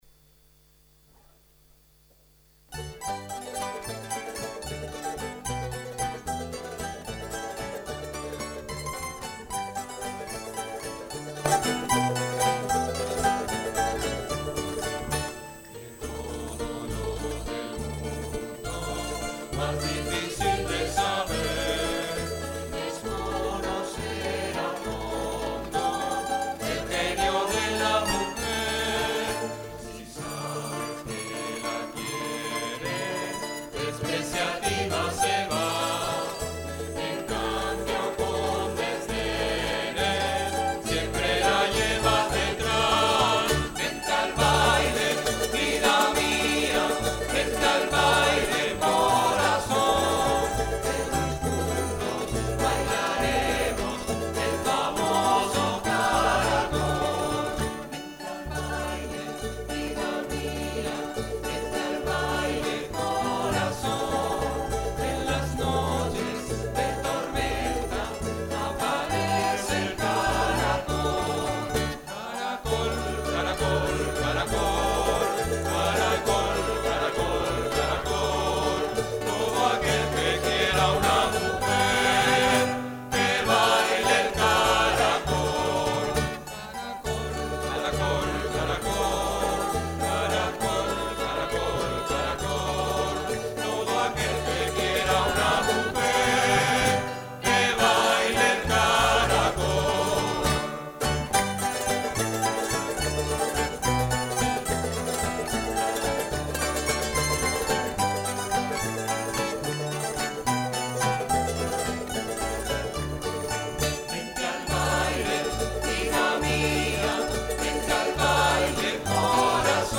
El jueves 16 de mayo, de 19:00 a 20:30 horas, se celebró en el salón de actos de la Facultad de Formación del Profesorado, con la participación del grupo "LasPalmeños ULPGC", siendo además su presentación oficial en la universidad con el nuevo nombre, y con nuevos temas.